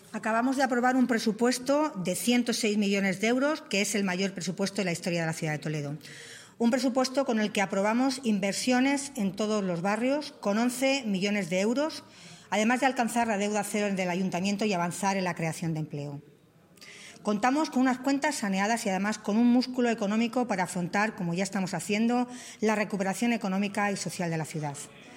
AUDIOS. Milagros Tolón, alcaldesa de Toledo
milagros-tolon_mayor-presupuesto-historia_deuda-cero_musculo-economico-afrontar-recuperacion.mp3